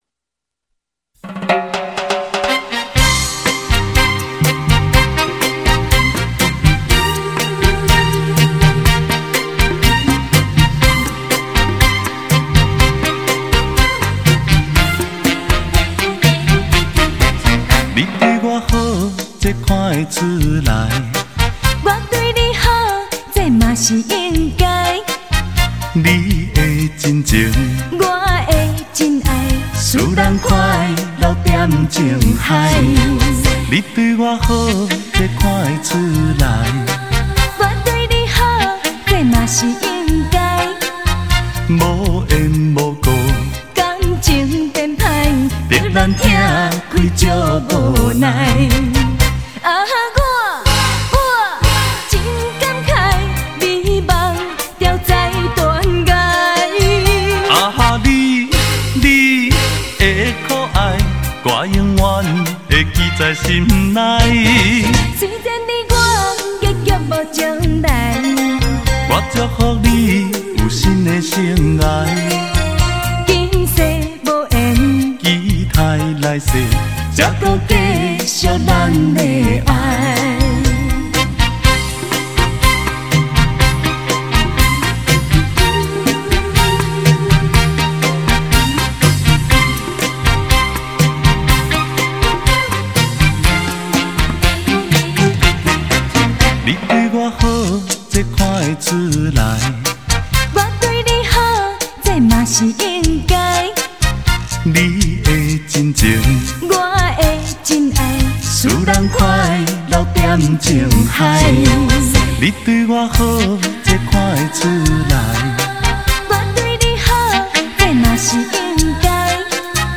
脍炙人口怀念歌曲 温馨甜蜜耐人回味
优美男女对唱 为您诠释台语经典情歌